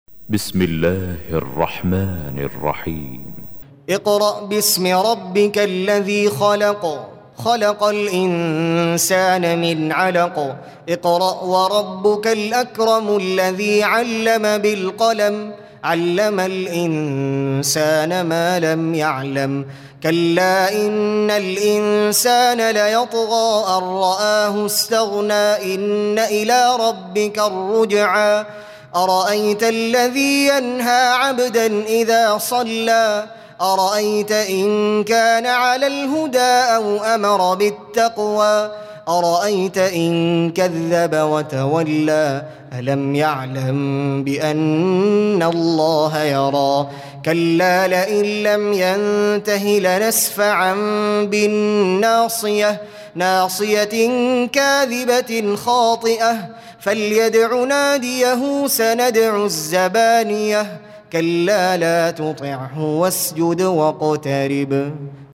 Audio Quran Tarteel Recitation
Surah Sequence تتابع السورة Download Surah حمّل السورة Reciting Murattalah Audio for 96. Surah Al-'Alaq سورة العلق N.B *Surah Includes Al-Basmalah Reciters Sequents تتابع التلاوات Reciters Repeats تكرار التلاوات